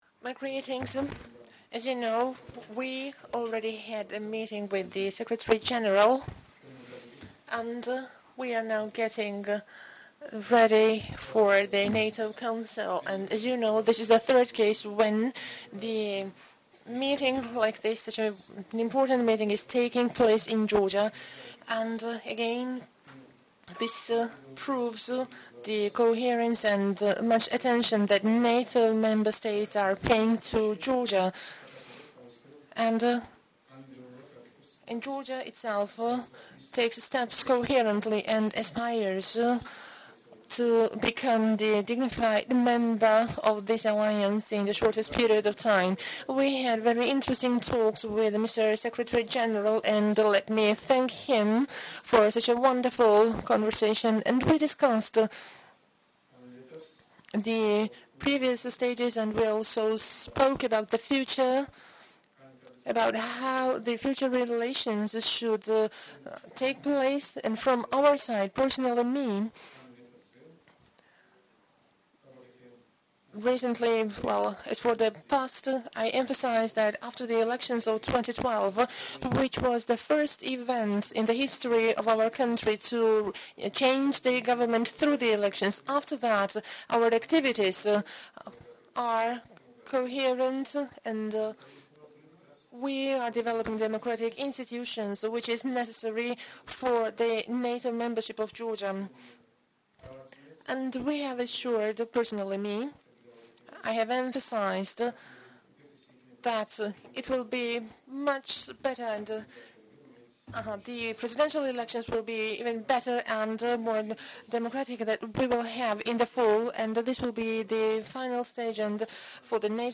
Introductory remarks by NATO Secretary General, Anders Fogh Rasmussen at the Press Point with the Prime Minister of Georgia, Bidzina Ivanishvili, Tbilisi, Georgia
Video NATO Secretary General with Prime Minister of Georgia - Joint Press Conference, 26 June 2013 26 Jun. 2013 Audio Joint press point with the Prime Minister of Georgia, Bidzina Ivanishvili and NATO Secretary General Anders Fogh Rasmussen 26 Jun. 2013 | download mp3